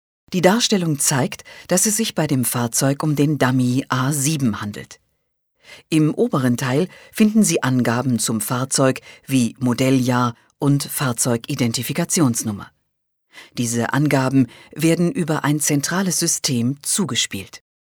deutsche Sprecherin.
Sprechprobe: Werbung (Muttersprache):
female german voice over talent.